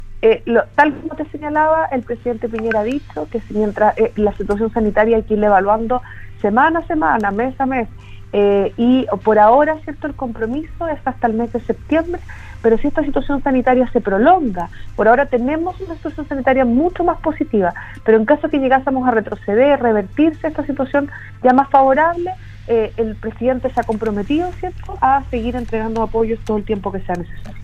En conversación con Radio Sago, la Subsecretaria de Evaluación Social, Alejandra Candia, comentó que el Gobierno accederá si las complicaciones sanitarias se prolongan.